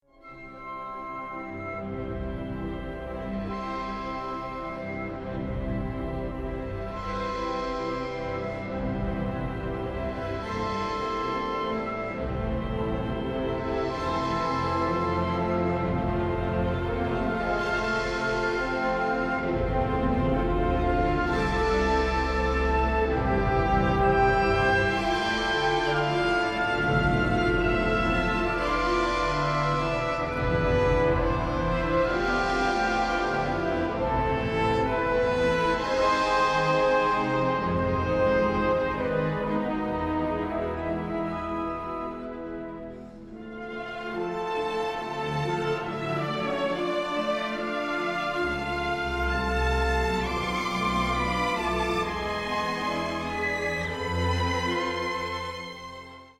Complete ballet in four acts